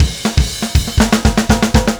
Power Pop Punk Drums 02 Fill B.wav